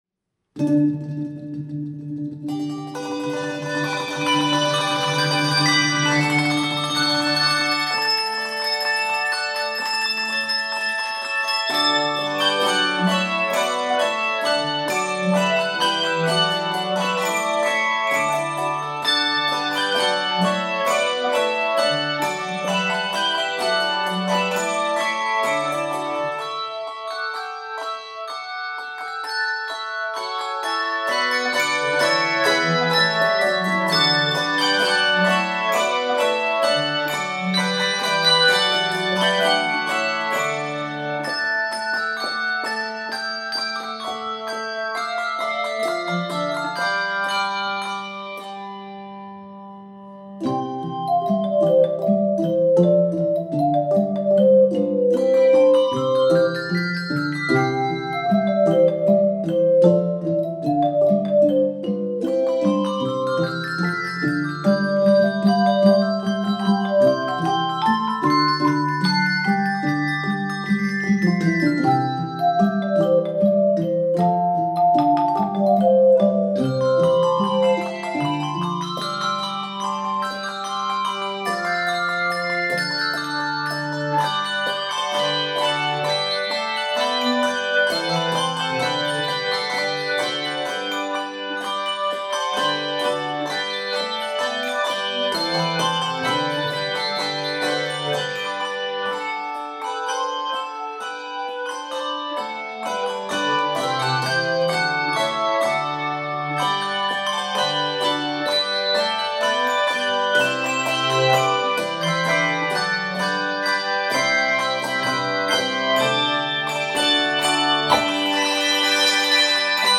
Keys of D Major and G Major.